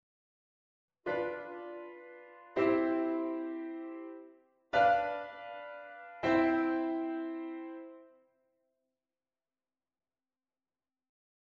oplossing van VII6